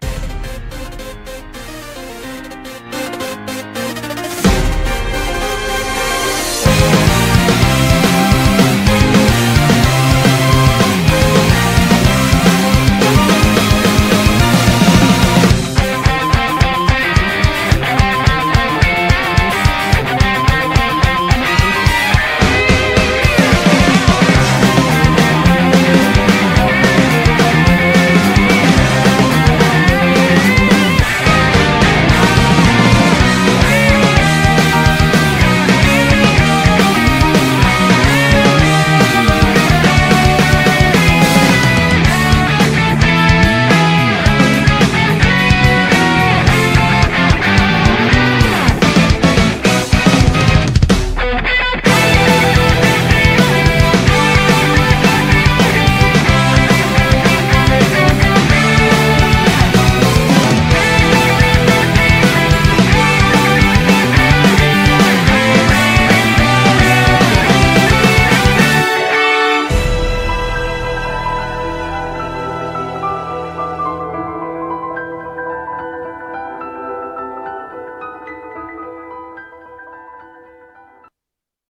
BPM217
Audio QualityCut From Video